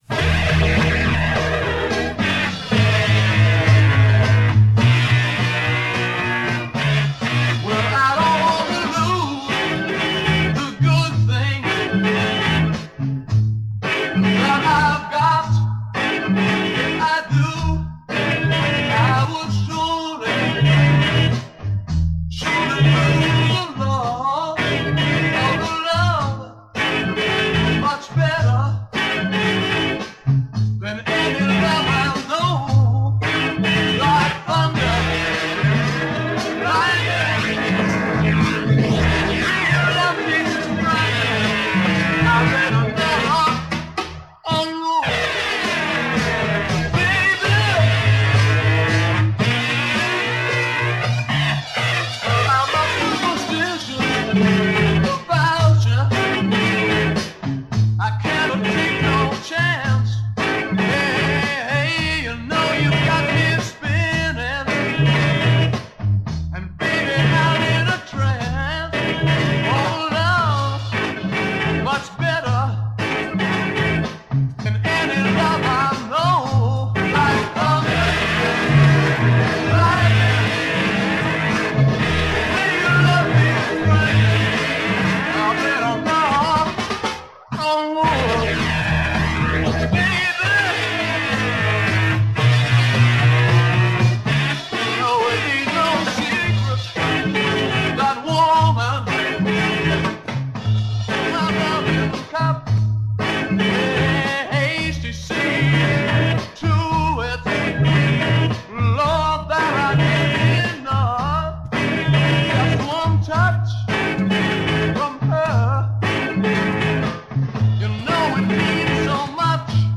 Recorded in the summer of 1967
lead guitar & back-up vocals
bass guitar
organ & back-up vocals
drums